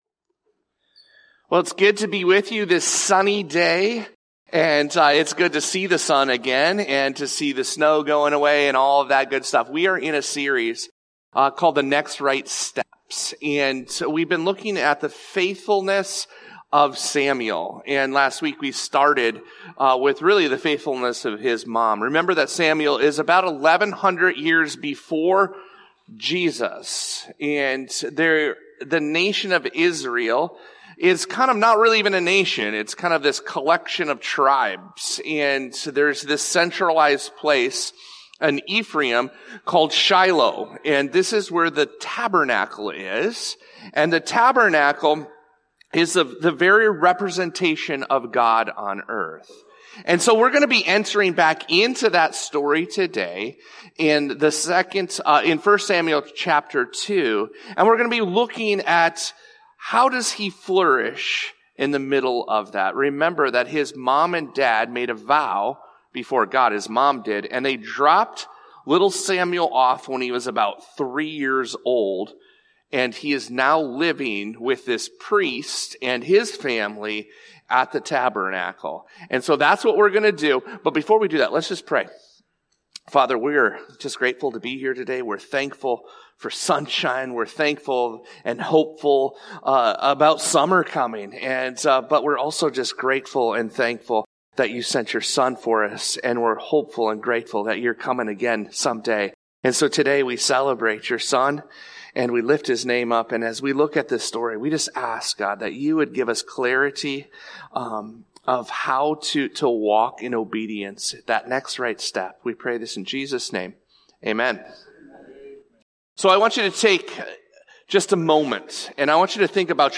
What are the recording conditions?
God calls us to take the next right step, even when the world around us won’t. The Scripture text is from 1 Samuel 2 and 3. This podcast episode is a Sunday message from Evangel Community Church, Houghton, Michigan, May 4, 2025.